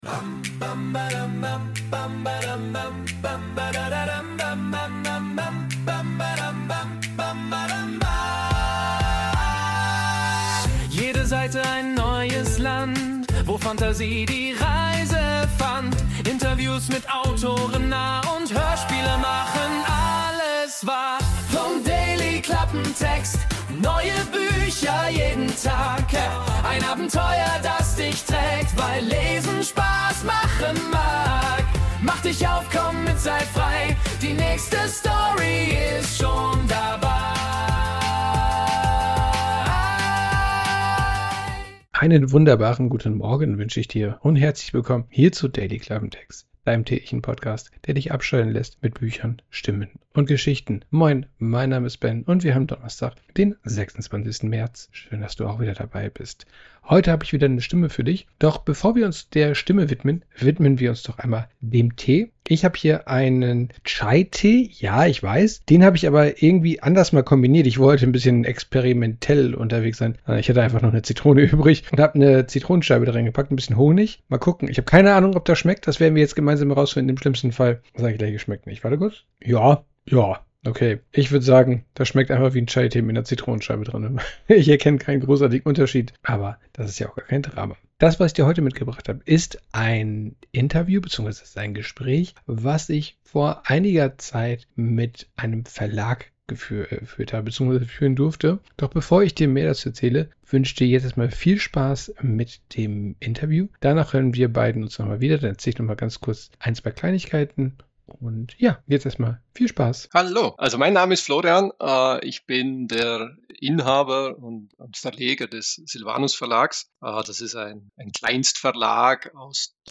Interview ~ Dailyklappentext Podcast